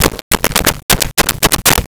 Switchboard Telephone Dial 01
Switchboard Telephone Dial 01.wav